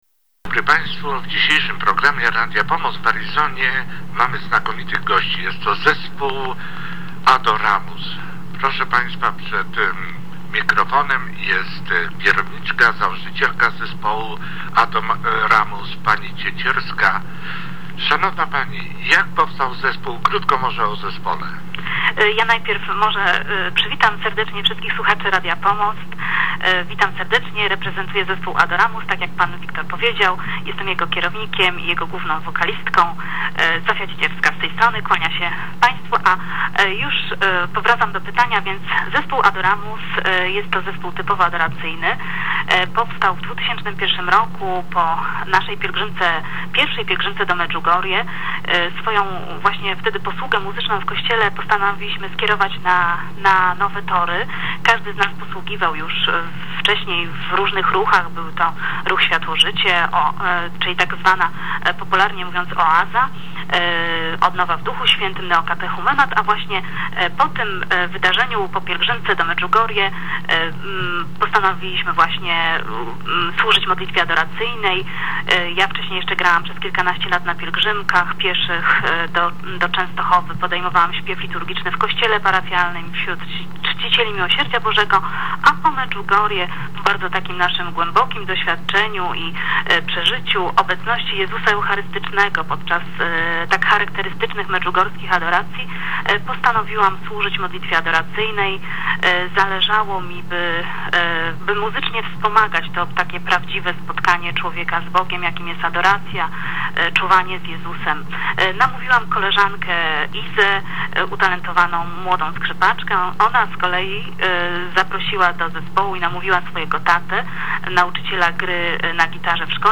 Wywiad dla Polonii – Radio Pomost 20.03.2008 cz. 1